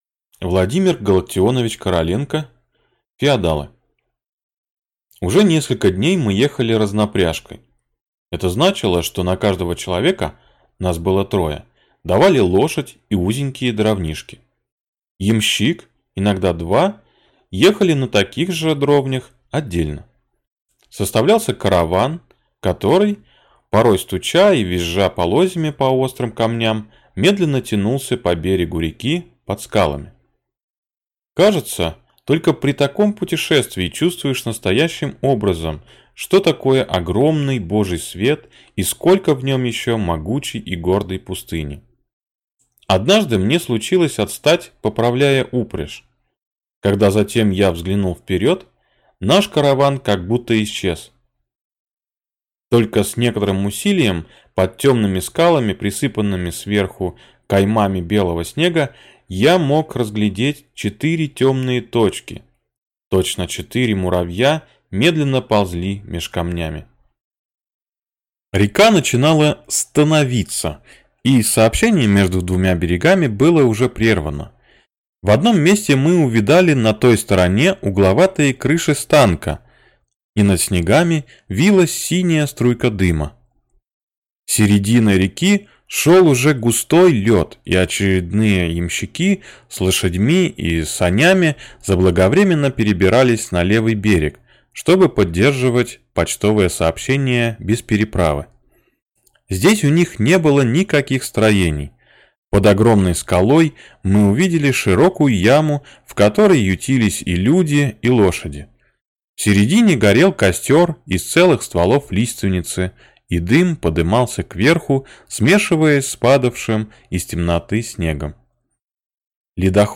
Aудиокнига Феодалы Автор Владимир Короленко Читает аудиокнигу